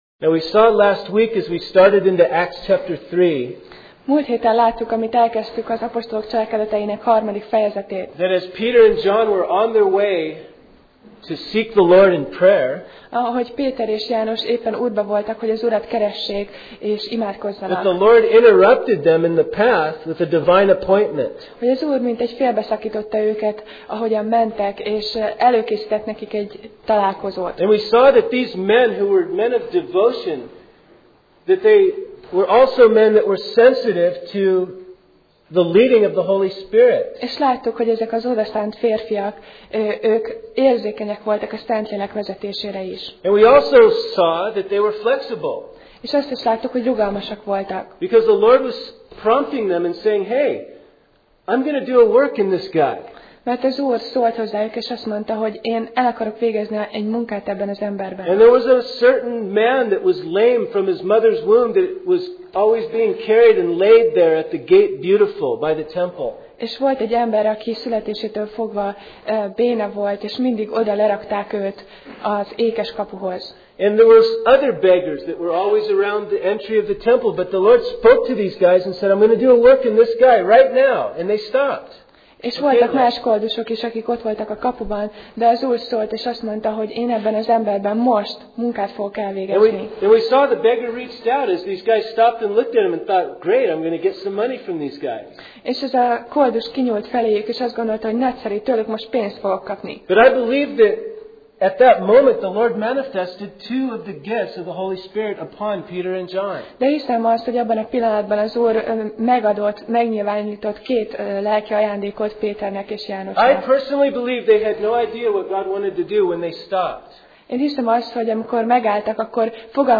Passage: Apcsel (Acts) 3:19-21 Alkalom: Vasárnap Reggel